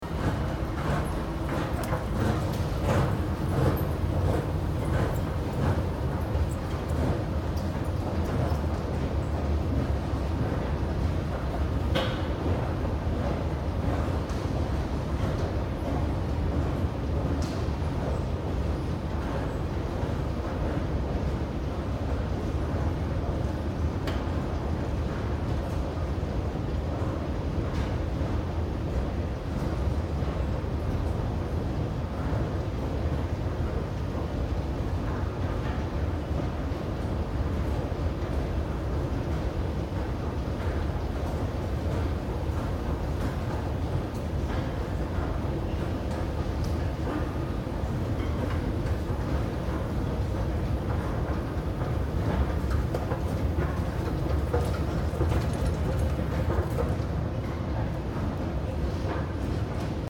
На этой странице собраны разнообразные звуки эскалаторов: от плавного гула двигателя до ритмичного стука ступеней.
Звук движения на эскалаторе: спуск